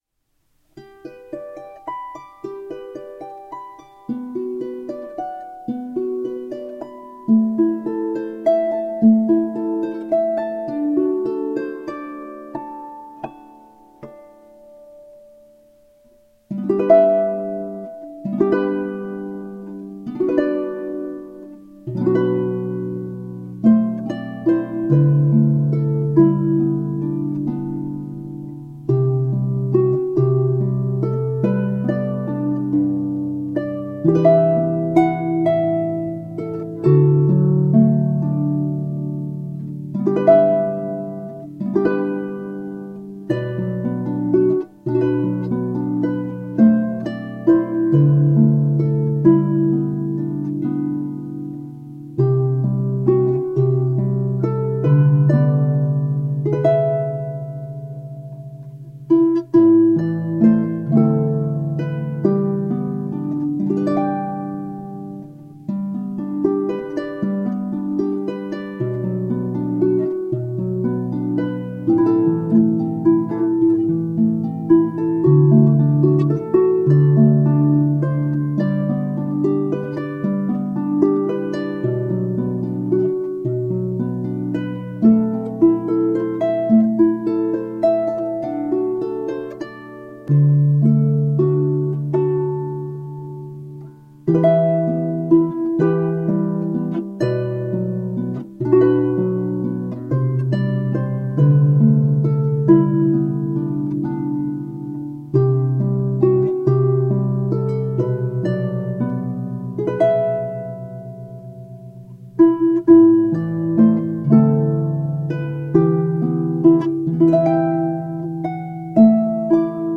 For Lever or Pedal Harp